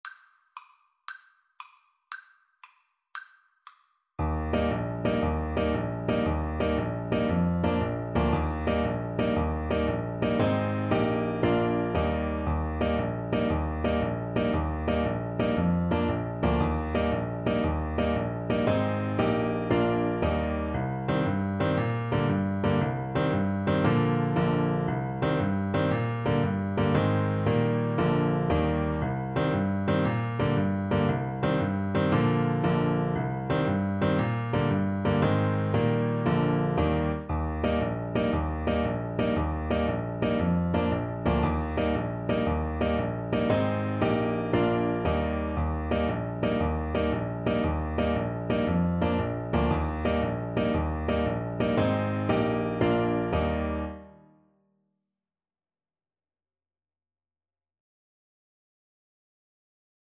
6/8 (View more 6/8 Music)
Eb major (Sounding Pitch) F major (Trumpet in Bb) (View more Eb major Music for Trumpet )
With energy .=c.116
Irish